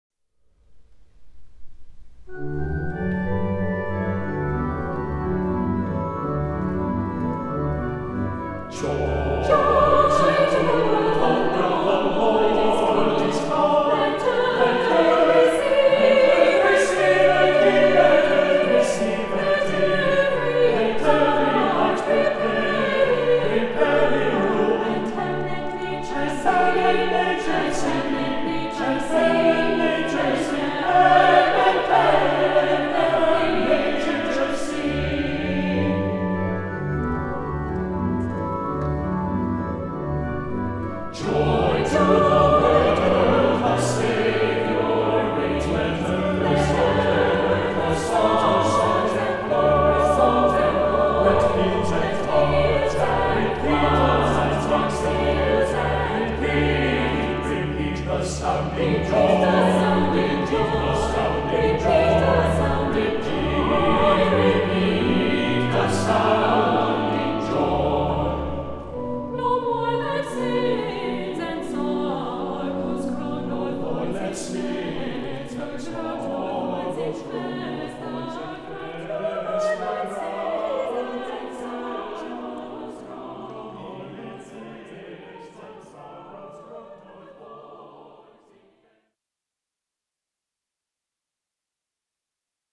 • Music Type: Choral
• Voicing: SATB, Soprano Solo, Tenor Solo
• Accompaniment: Organ